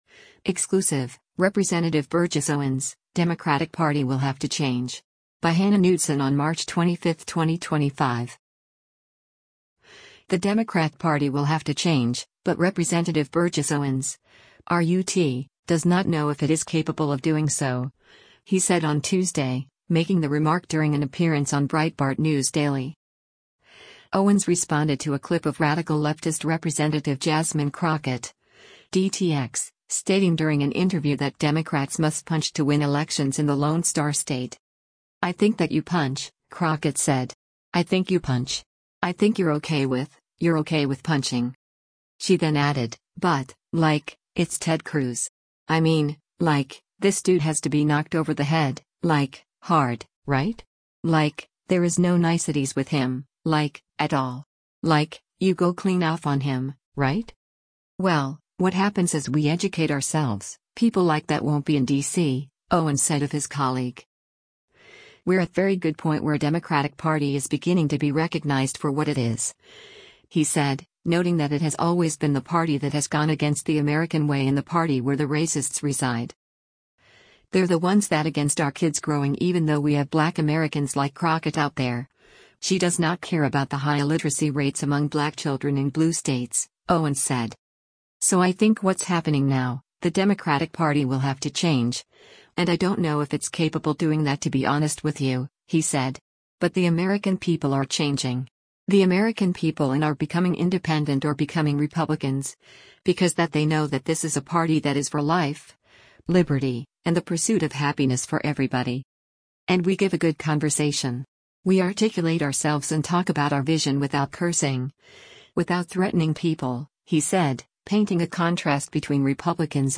The Democrat Party will “have to change,” but Rep. Burgess Owens (R-UT) does not know if it is capable of doing so, he said on Tuesday, making the remark during an appearance on Breitbart News Daily.